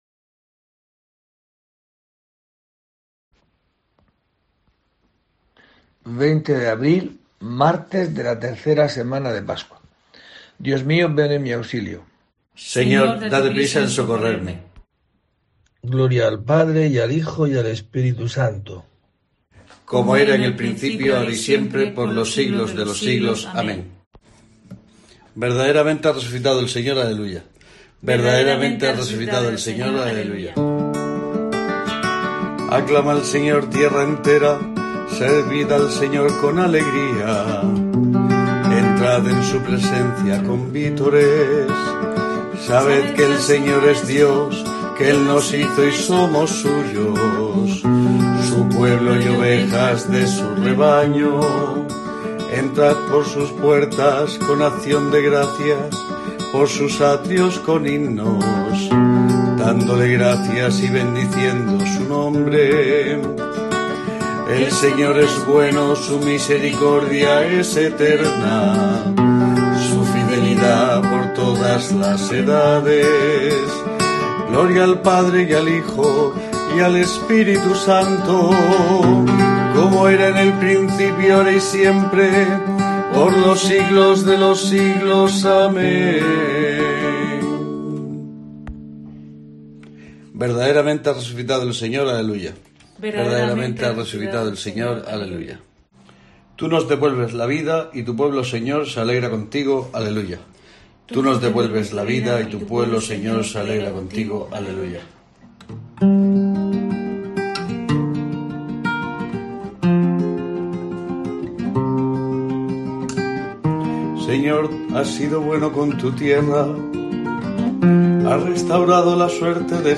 La oración desde la parroquia de Santa Eulalia de Murcia, pronunciada en este día